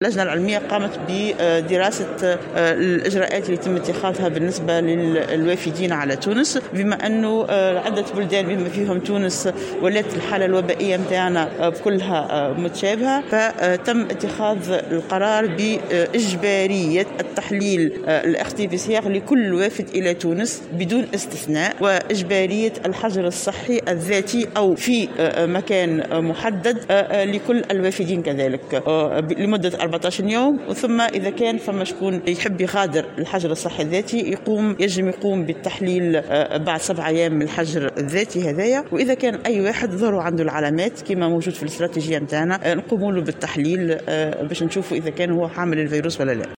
وأفادت بن علية في تصريح للجوهرة أف أم ، بأن اللجنة العلمية قامت بدراسة الإجراءات التي يتم إتخاذها بالنسبة للوافدين من الخارج ، وتم إتخاذ قرار بإجبارية إخضاع كل الوافدين على تونس وبدون إستثناء لتحليل PCR وإجبارية الحجر الصحي الذاتي لمدة 14 يوما .